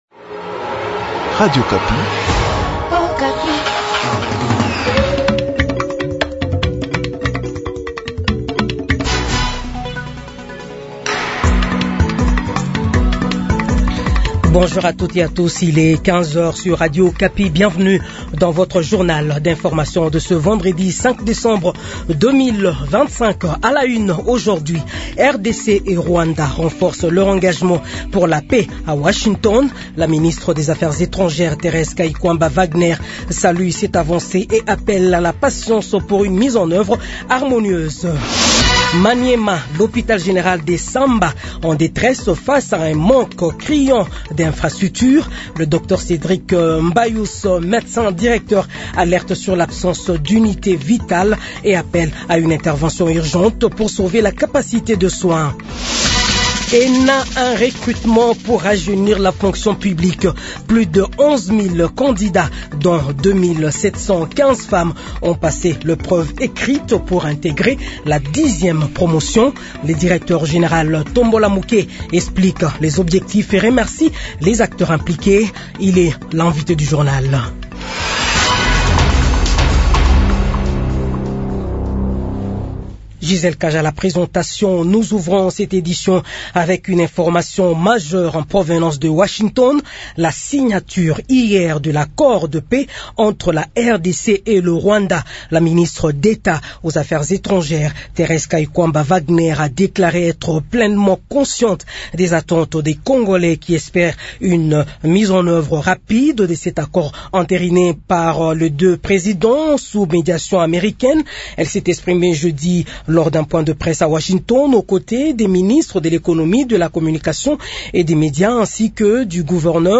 Journal 15h